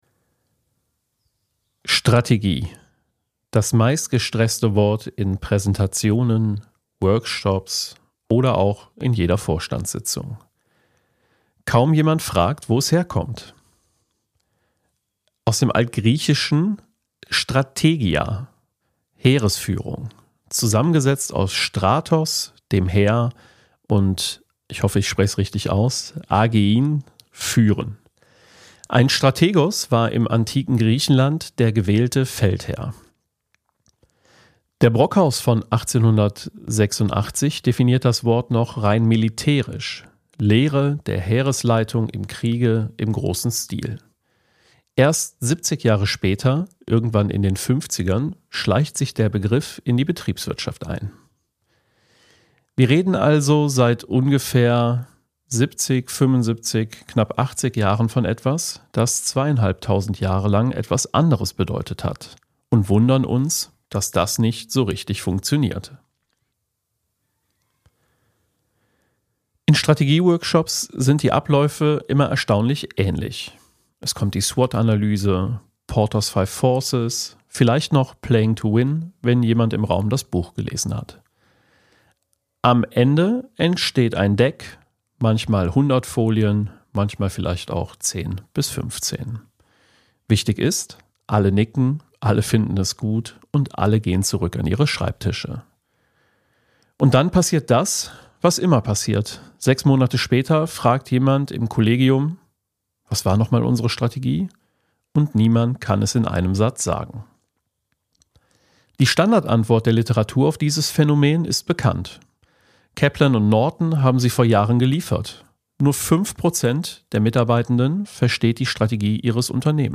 Kein Jingle, kein Small Talk.